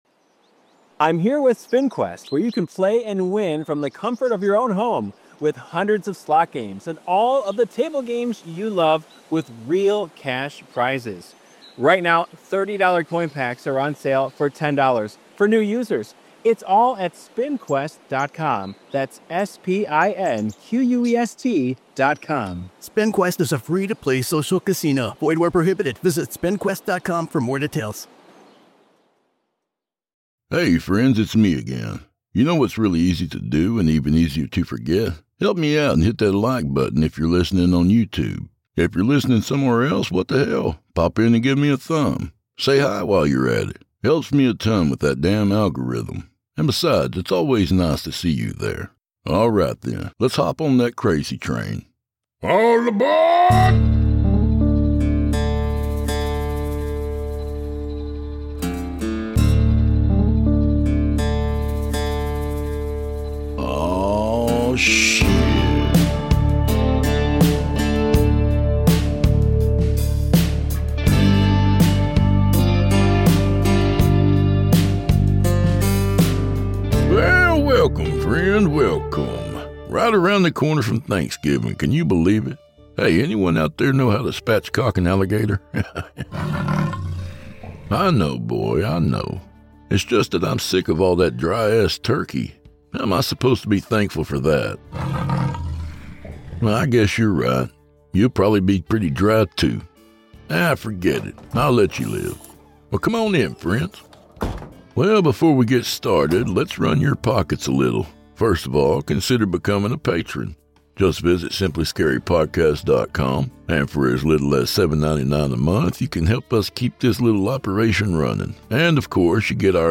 A Horror Fiction Anthology and Scary Stories Podcast